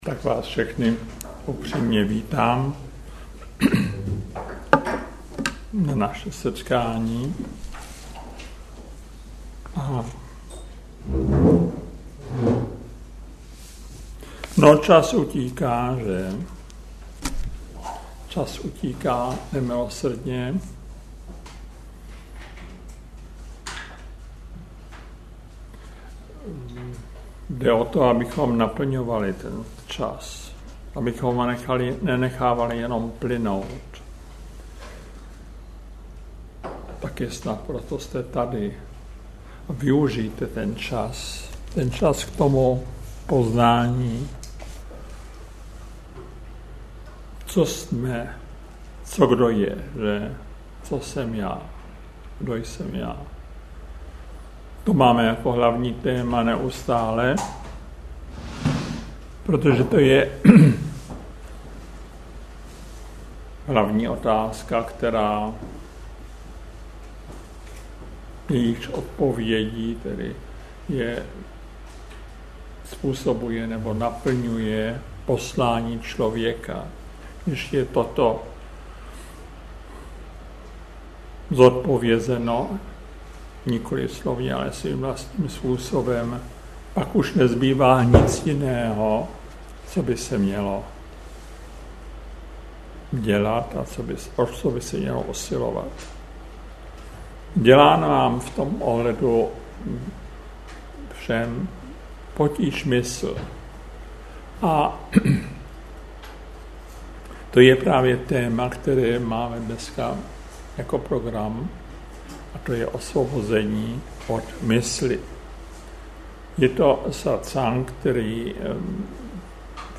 Veřejné přednášky 2006